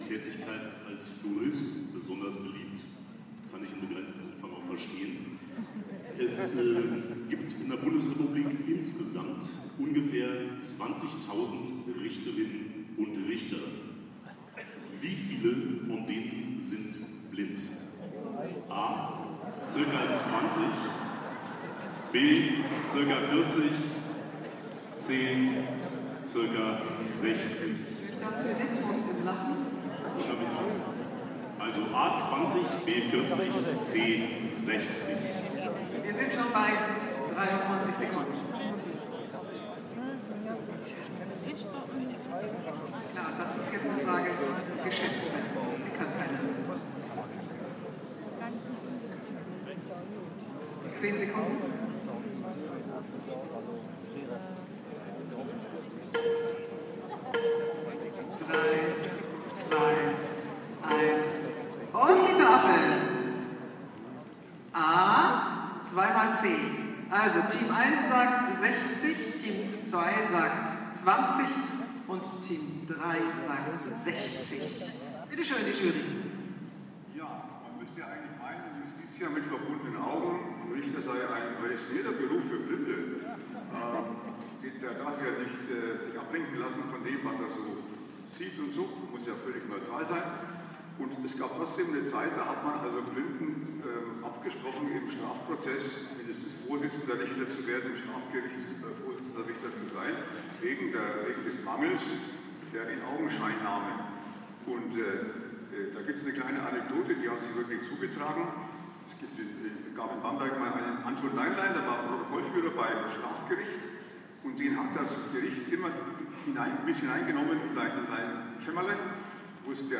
DBSV-Festival: Quiz-Ausschnitt